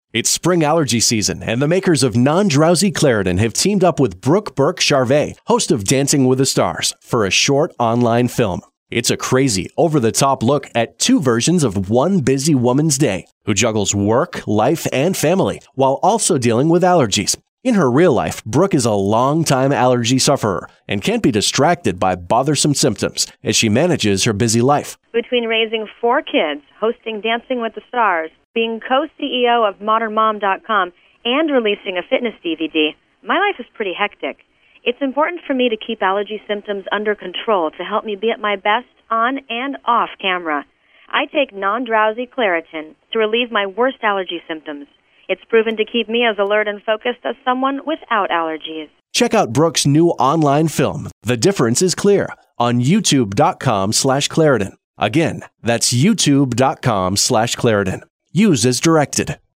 April 16, 2012Posted in: Audio News Release